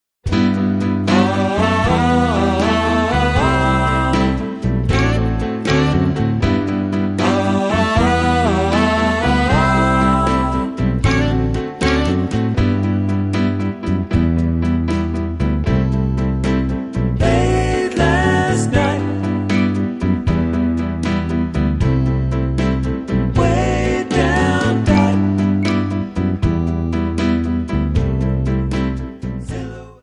Backing track files: Oldies (1113)
Buy With Backing Vocals.